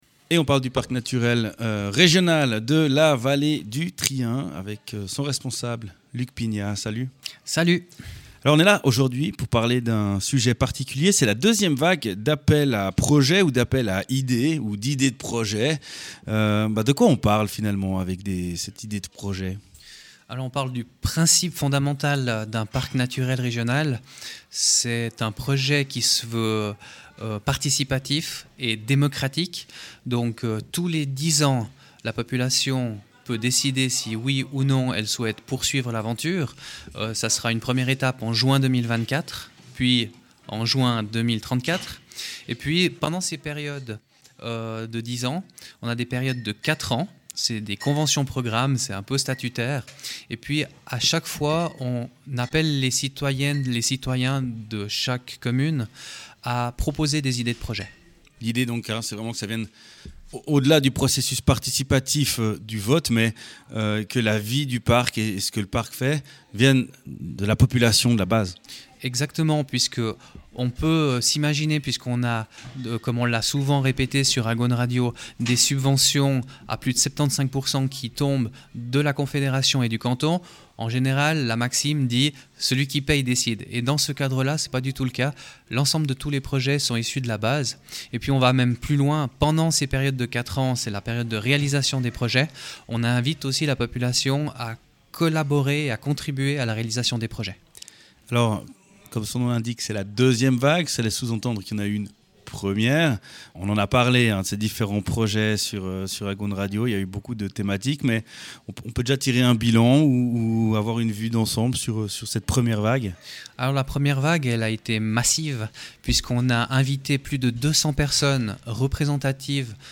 Podcast enregistré dans les conditions du direct au Restaurant de la Dent-du-Midi à Saint-Maurice.